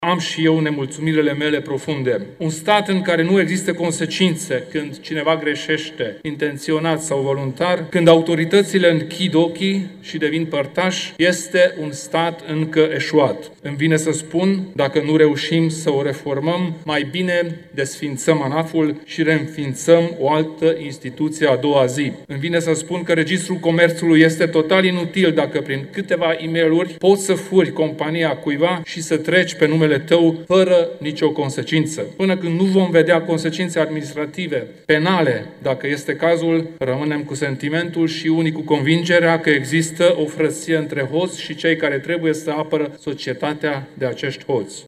În timpul dezbaterilor, președintele UDMR, Kelemen Hunor a spus că e nemulțumit de funcționarea statului și de faptul că nu sunt trași la răspundere cei care greșesc. Acesta a criticat instituții precum ANAF și Registrul Comerțului.